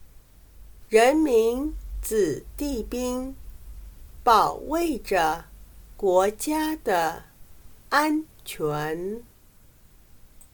人们子弟兵保卫着国家的安全。/Rénmen zǐdìbīng bǎowèizhe guójiā de ānquán./Ejército chino y los soldados defienden la seguridad del país.